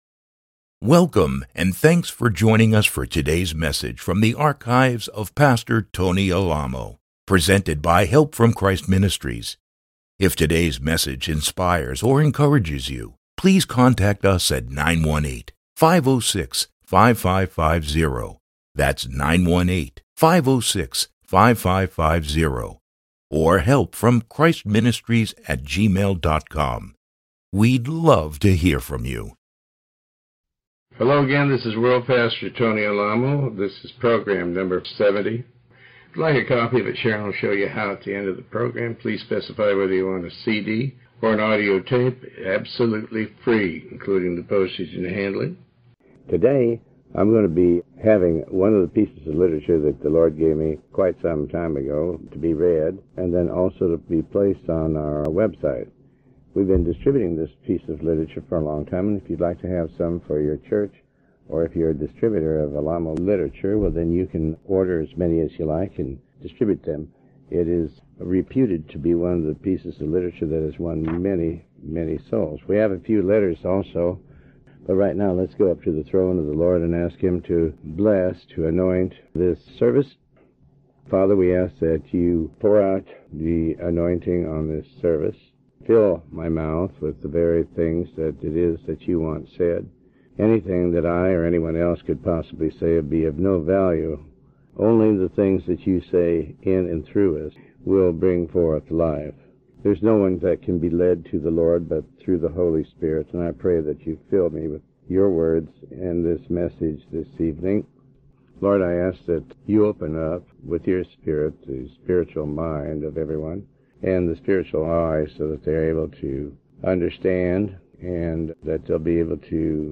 Sermon 70B